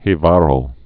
(hēvä-rō)